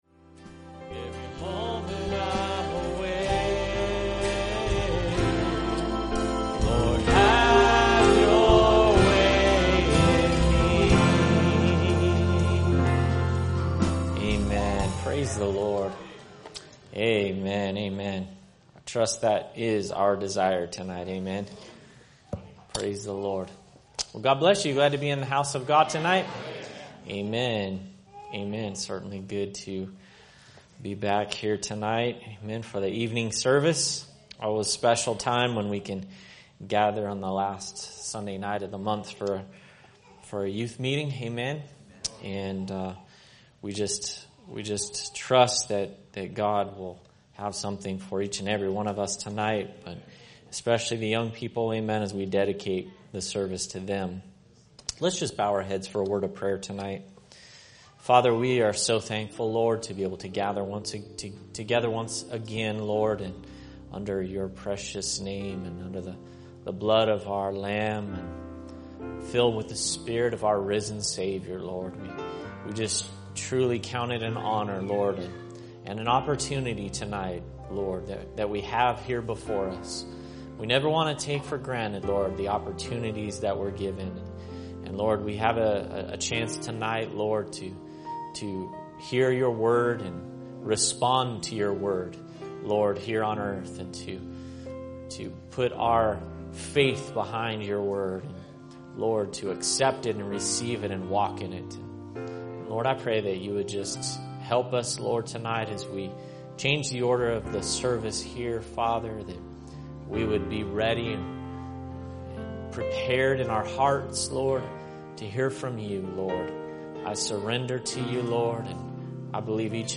Youth Meeting